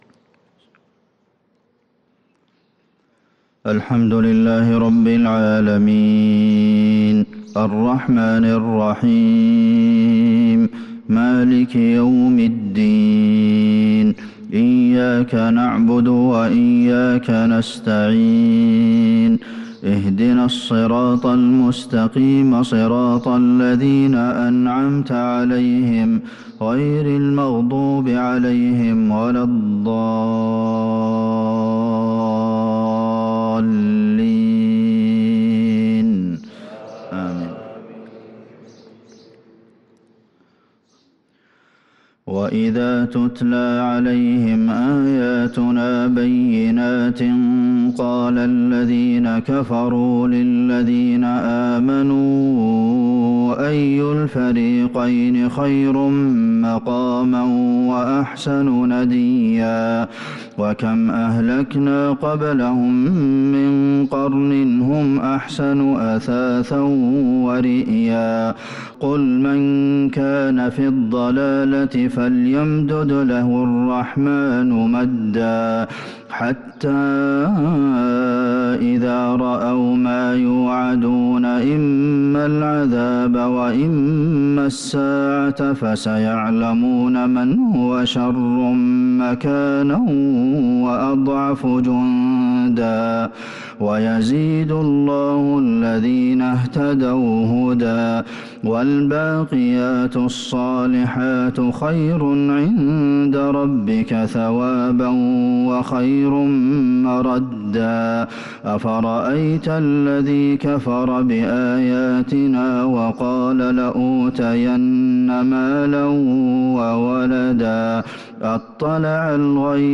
صلاة الفجر للقارئ عبدالمحسن القاسم 24 شعبان 1443 هـ
تِلَاوَات الْحَرَمَيْن .